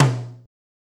(TOM) The Pluggz Tom C.wav